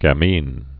(gă-mēn, gămēn)